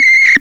Le fonctionnement est bizarre, car les sons qui sortent sont différents après chaque utilisation - mais pas tout le temps.
Vous aussi vous voulez utiliser le Synthétiseur Aléatoire du Microcosme ?